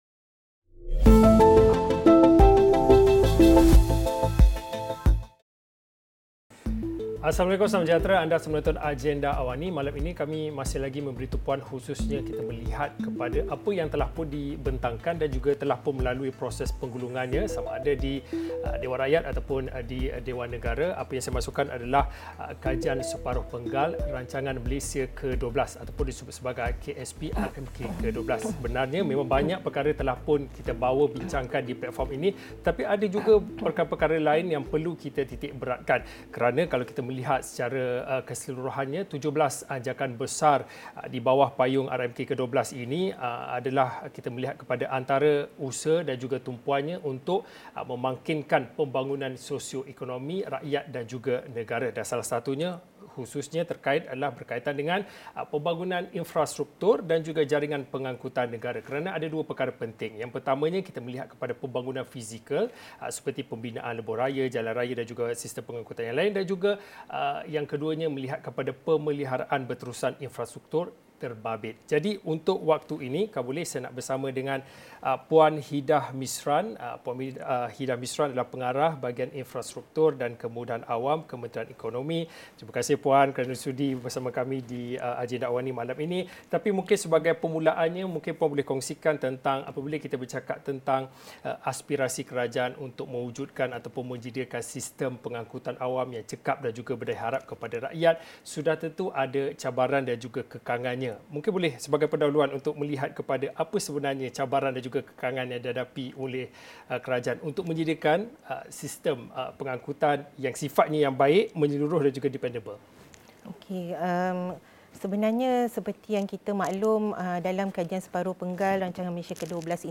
Diskusi 9 malam.